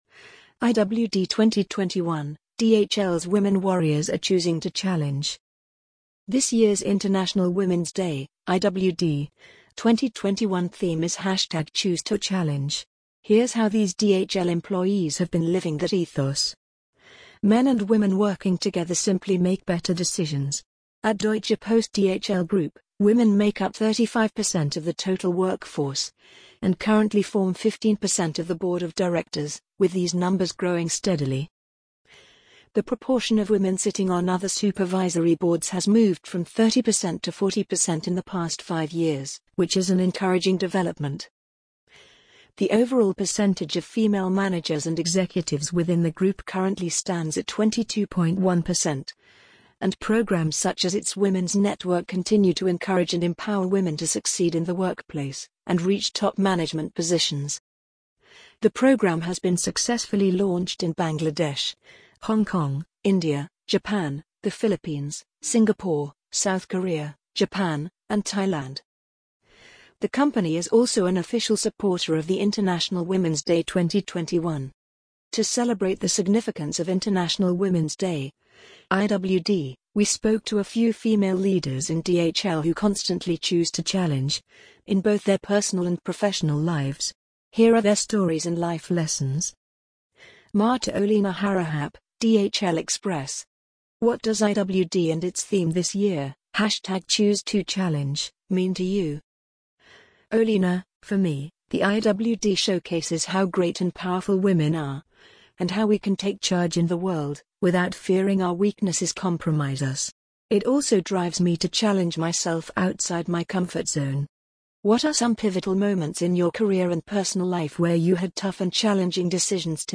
amazon_polly_10801.mp3